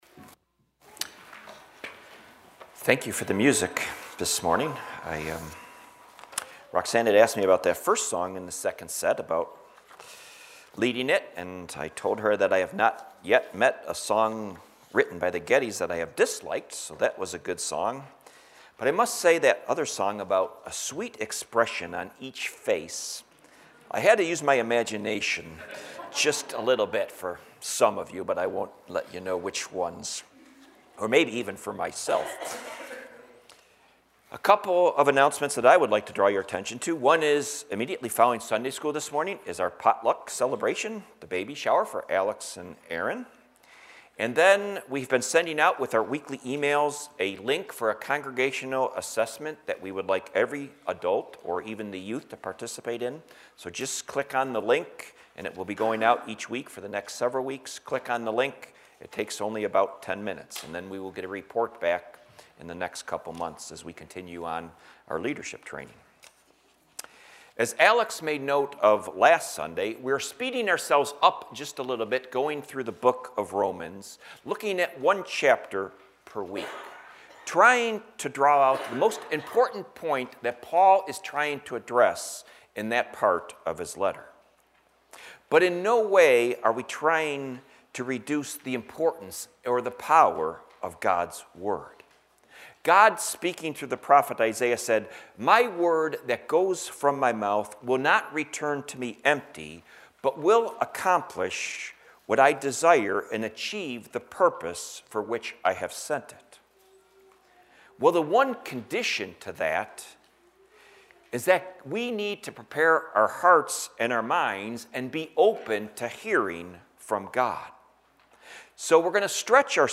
Service 9:30 am Worship